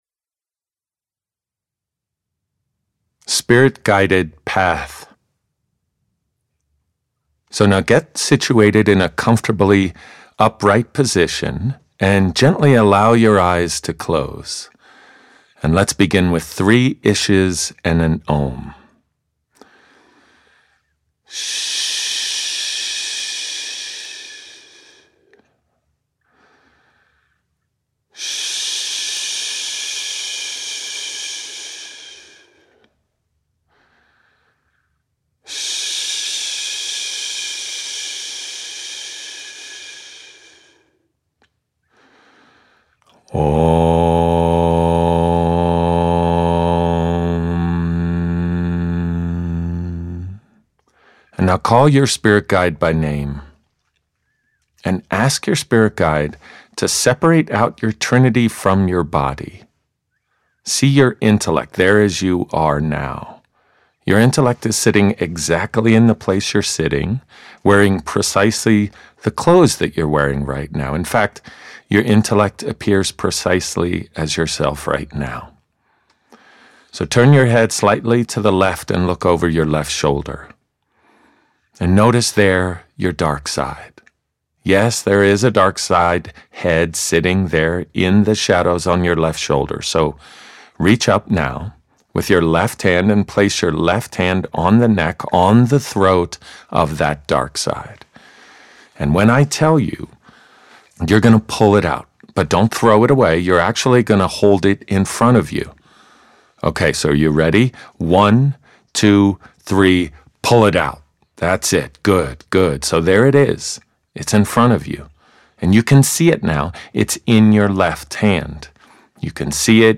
Spirit-Guided Path visualization